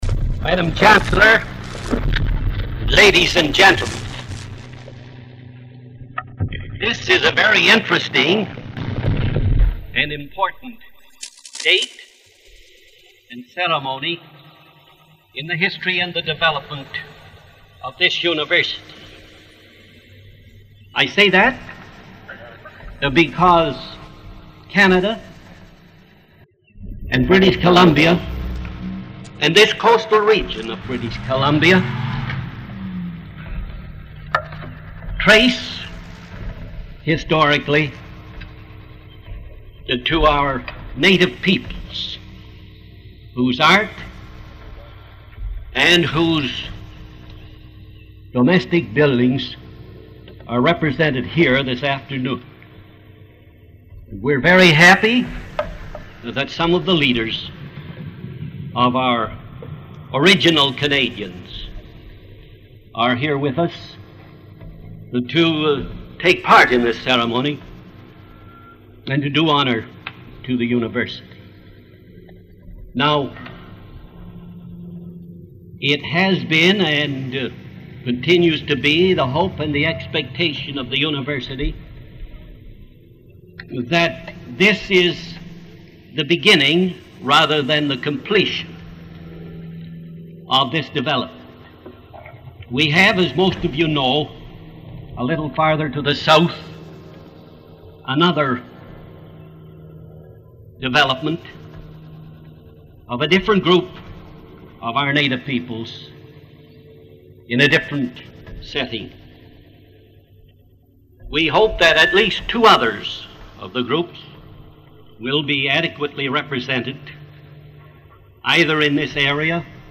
[Opening ceremony for Haida section of Totem Pole Park]